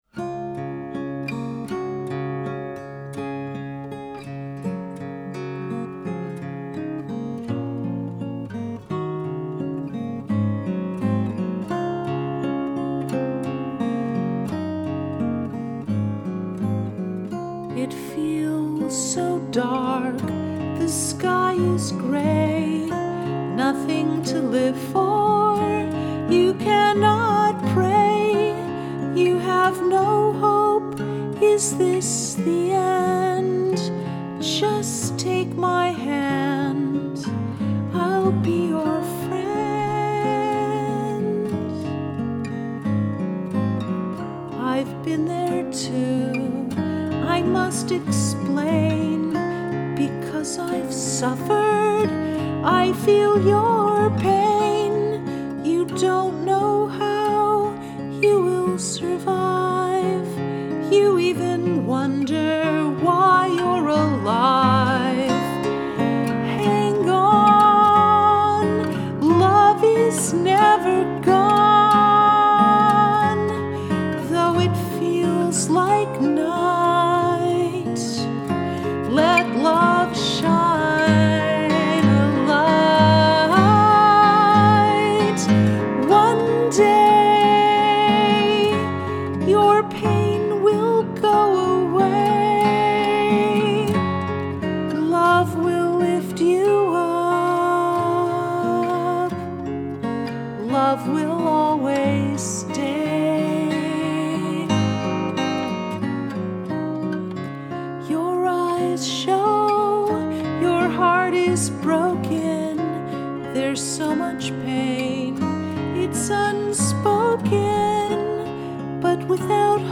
I purchased a new digital recorder and loved the bright and clear sound.
For several months I had been recording myself at home.
hang-on-acoustic-mix-9.mp3